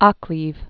(ŏklēv), Thomas